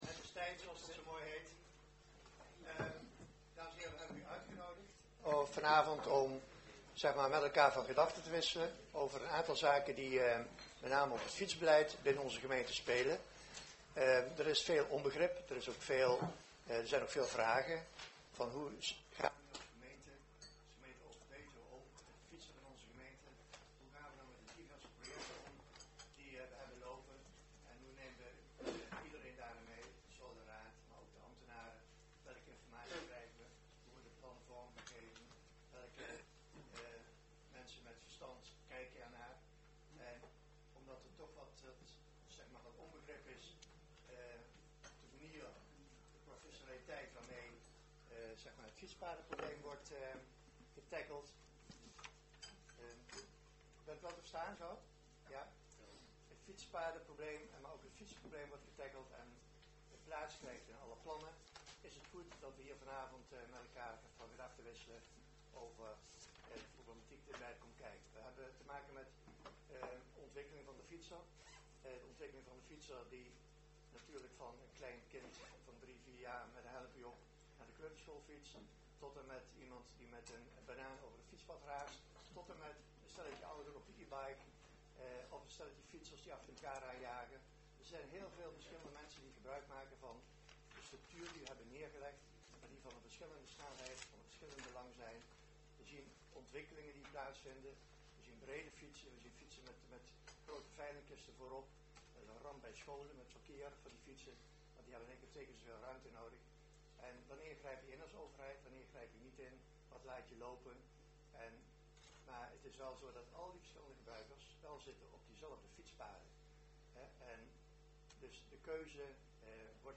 Commissiekamer, gemeentehuis Elst